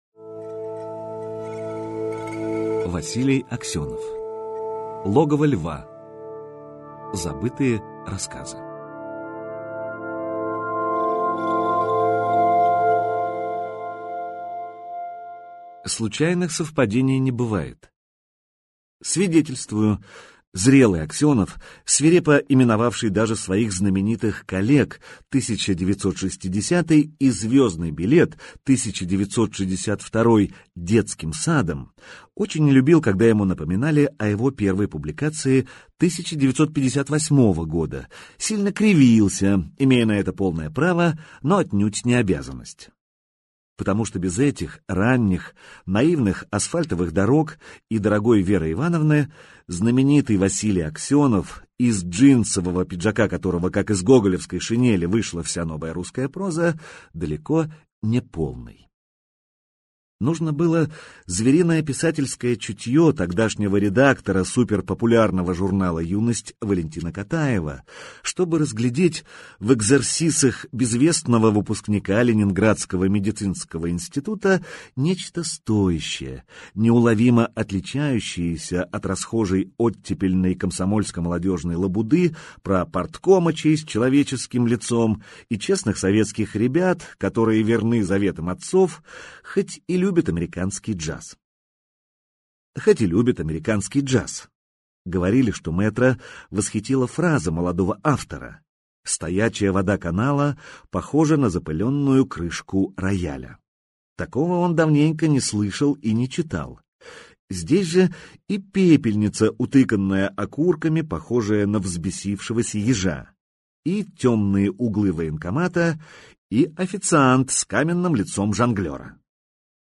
Аудиокнига Логово Льва. Забытые рассказы | Библиотека аудиокниг